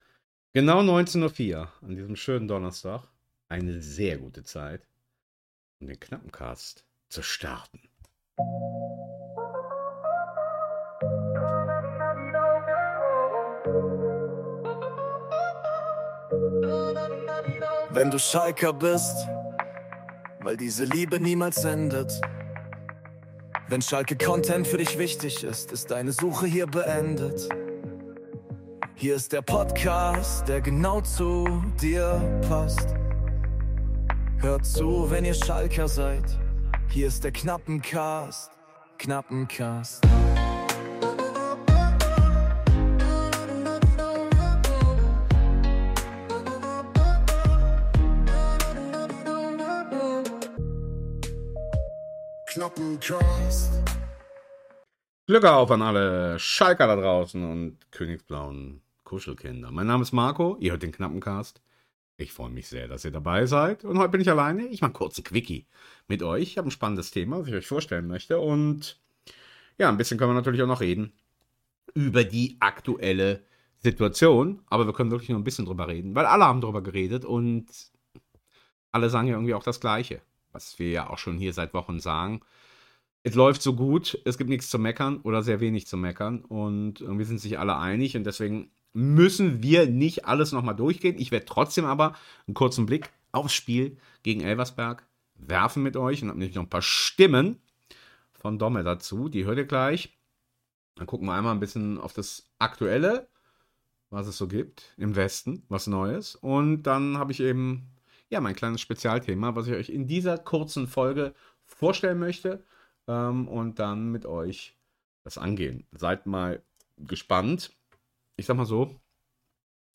Obendrein gibts in der aktuellen Ausgabe noch ein paar schöne stimmliche Eindrücke vom Elversberg-Spiel und dem Bildungsauftrag folgend die Defintion eines schwachen Verbes.